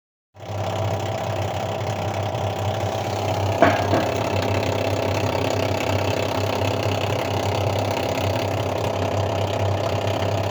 Скажите пожалуйста по какой причине машинка не качает грязную воду и издает рокот